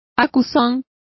Also find out how acuson is pronounced correctly.